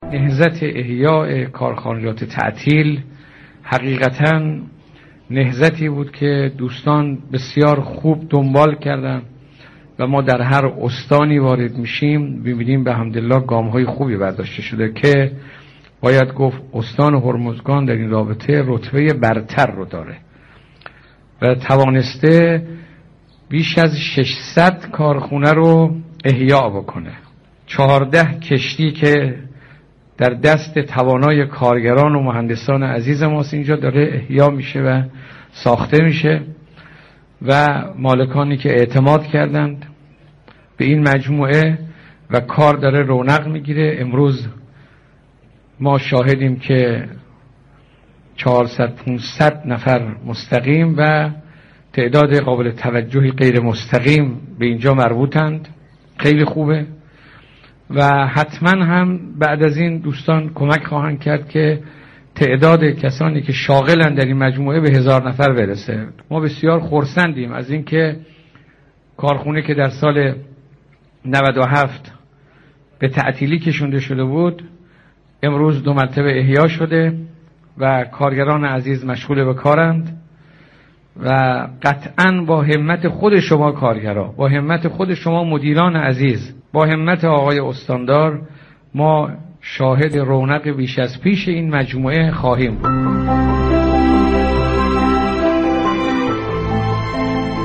به گزارش شبكه رادیویی ایران، این مستند رادیویی با پخش مستنداتی از رییس جمهور شهید گفت: استان هرمزگان رتبه برتر نهضت احیای كارخانه های تعطیل است و توانسته بیش از 600 كارخانه را دوباره فعال كند.